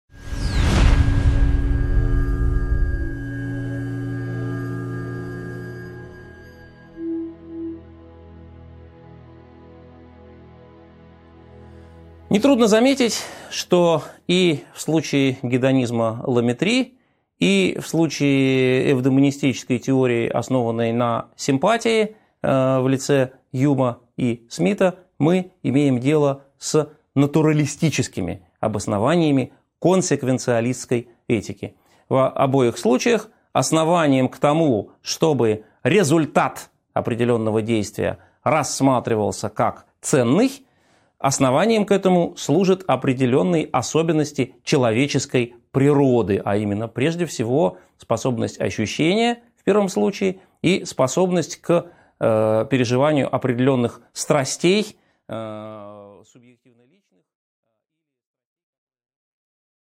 Аудиокнига 11.5 Эвдемонизм (продолжение) | Библиотека аудиокниг